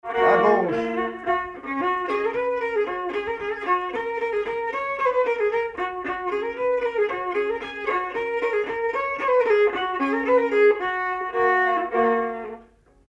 Mazurka partie 2
danse : mazurka
circonstance : bal, dancerie
Pièce musicale inédite